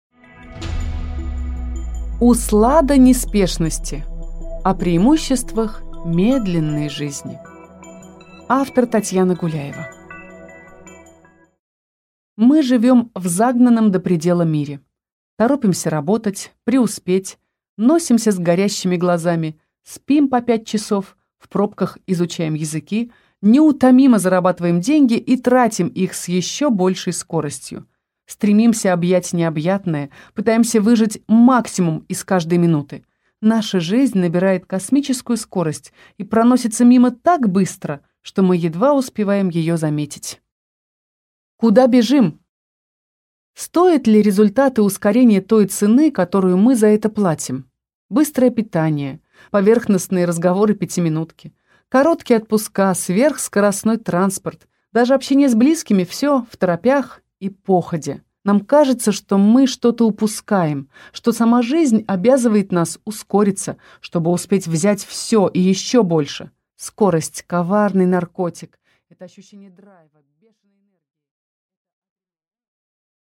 Аудиокнига Услада неспешности | Библиотека аудиокниг
Прослушать и бесплатно скачать фрагмент аудиокниги